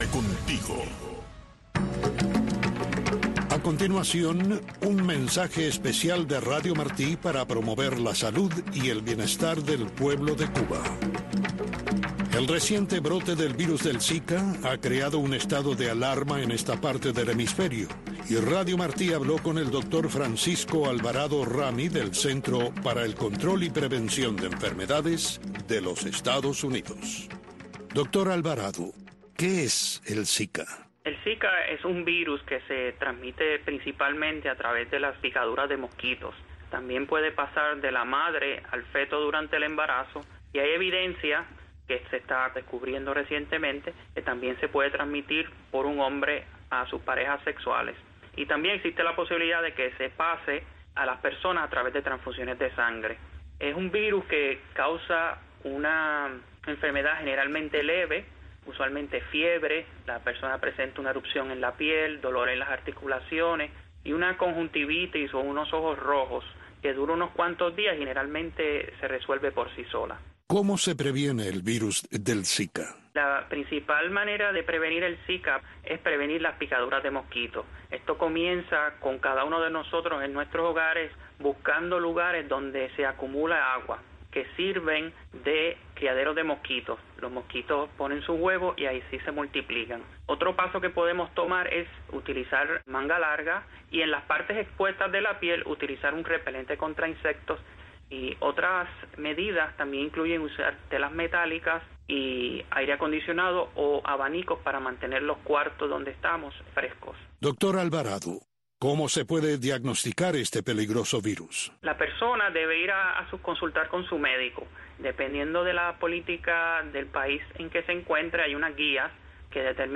Soy Guajiro es un programa para los campesinos y guajiros con entrevistas, música y mucho más.